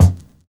VINYL 13 BD.wav